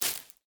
Minecraft Version Minecraft Version snapshot Latest Release | Latest Snapshot snapshot / assets / minecraft / sounds / block / cobweb / break5.ogg Compare With Compare With Latest Release | Latest Snapshot